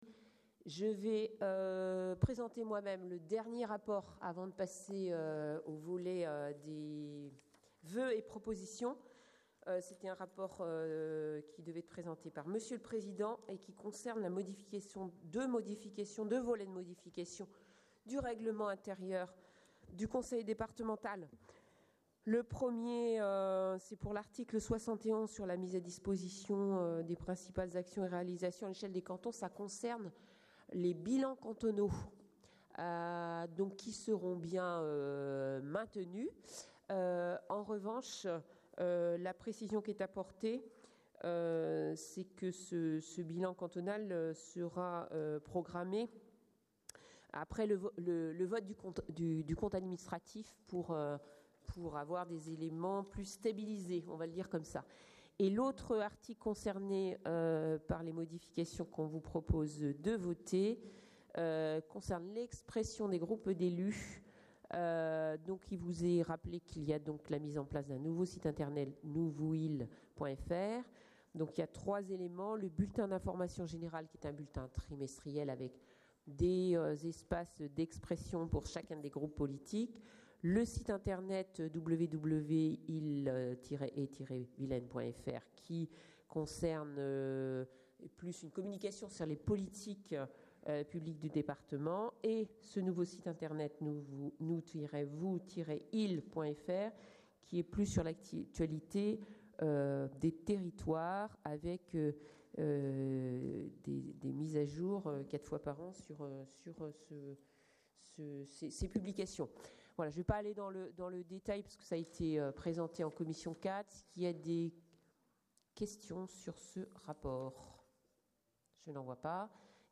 • Assemblée départementale du 16/11/23